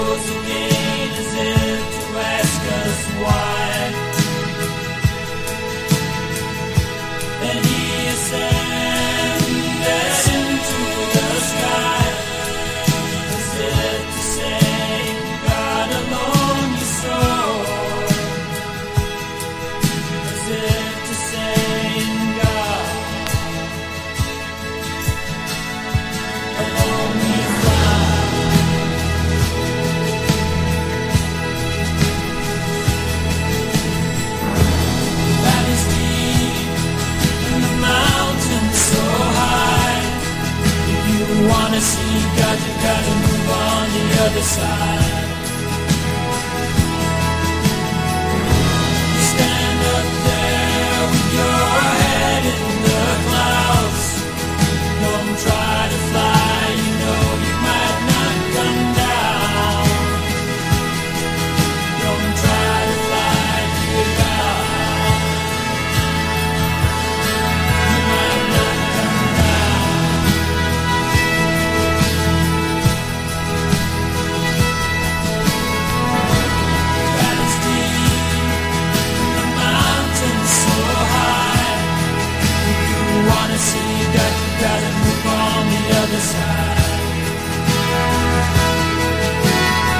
1. 70'S ROCK >
シンフォニックで壮大な叙情派フォークロック絵巻！